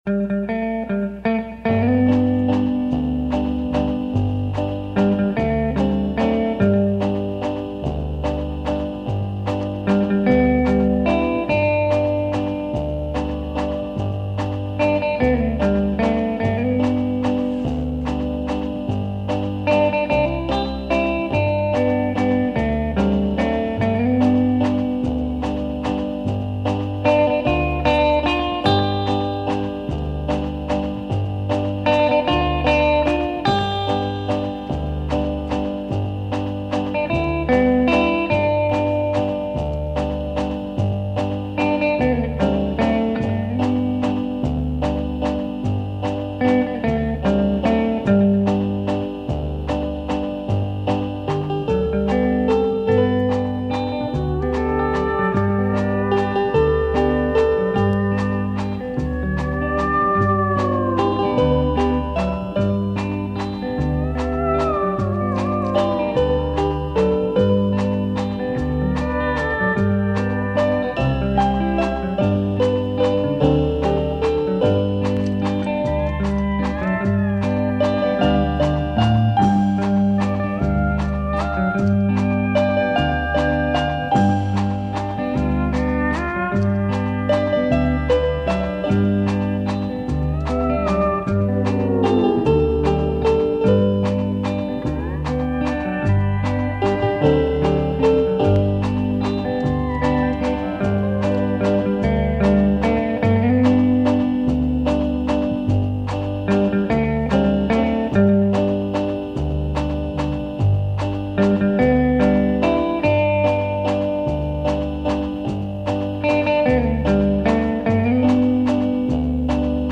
The instrumental
flavored by tropical steel guitar.